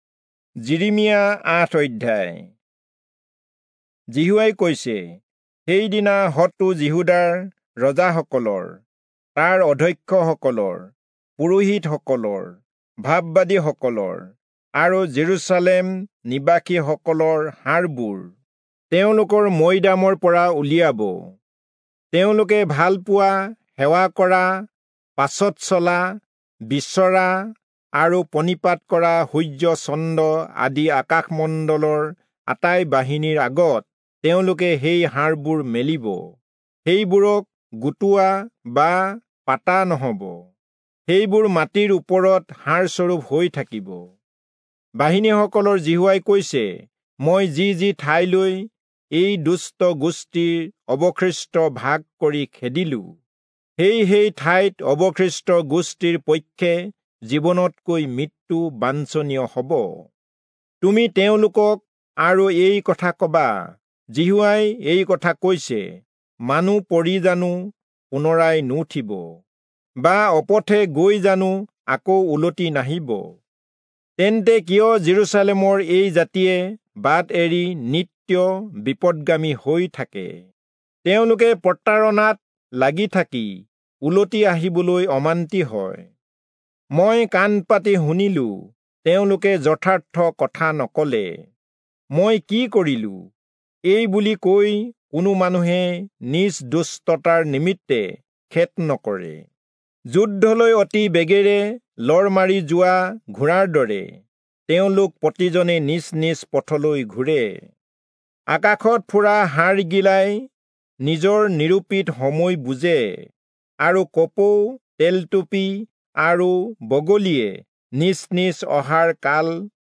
Assamese Audio Bible - Jeremiah 46 in Irvbn bible version